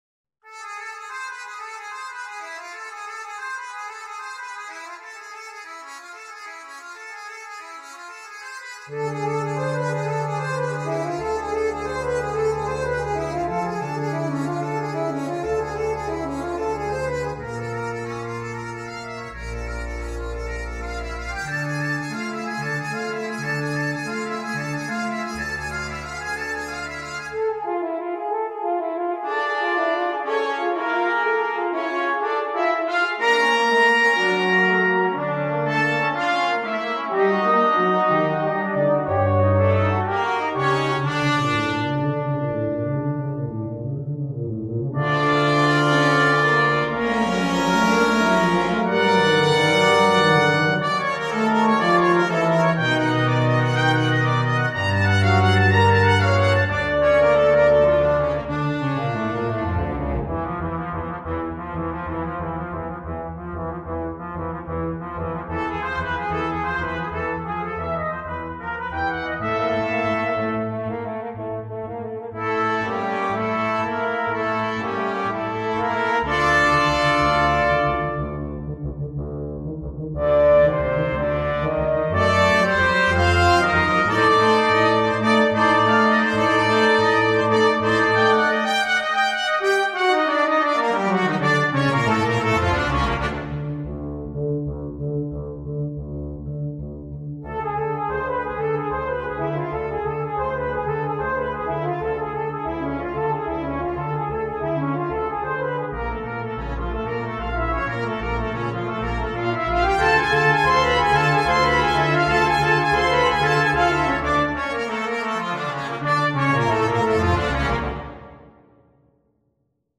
sans instrument solo
Partie 1: Trompette mib, Cornet à Pistons mib
Partie 3: Cor d’harmonie
Partie 4: Trombone – Clé de fa
Partie 5: Tuba